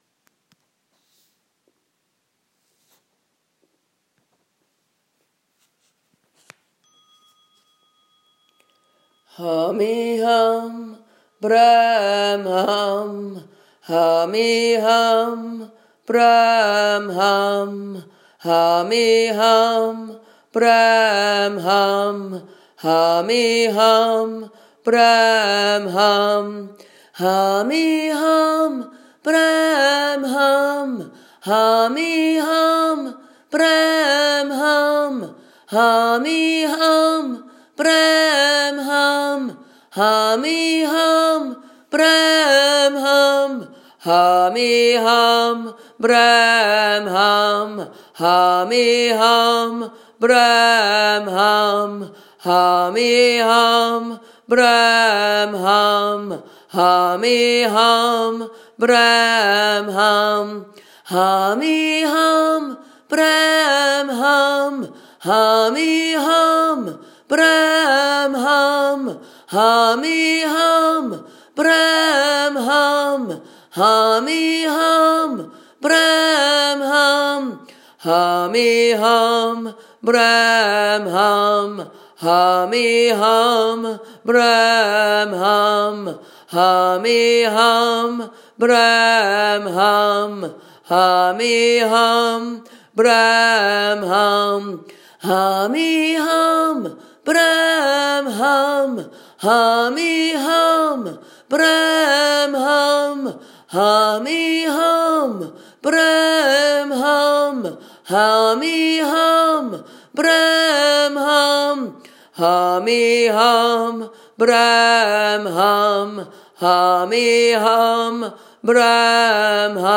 Mantra – Humee Hum Brahm Hum (Fr)